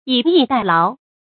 yǐ yì dài láo
以逸待劳发音
成语正音 逸，不能读作“tuì”。